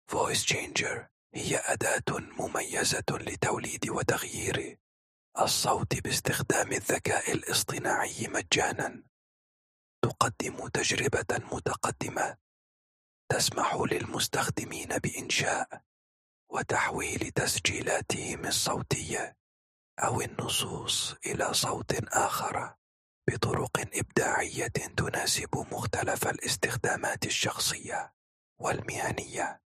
VoiceChanger هي أداة مميزة لتوليد وتغيير الصوت باستخدام الذكاء الاصطناعي مجاناً.
إليك مثالين لإنشاء وتحويل الصوت